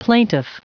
Prononciation du mot plaintiff en anglais (fichier audio)
Prononciation du mot : plaintiff